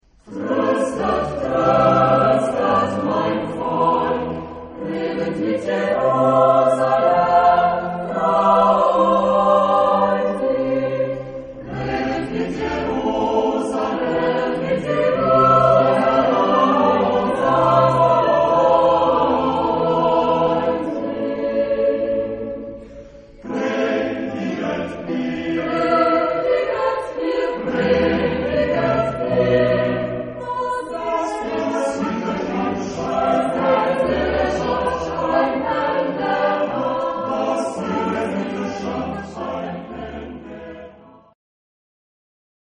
Genre-Style-Form: Choir
Mood of the piece: broad ; collected
Type of Choir: SSATTB  (6 mixed voices )
Tonality: G minor
sung by Choeur des XVI (CH) ; Dresdner Kammerchor conducted by Hans-Christoph Rademann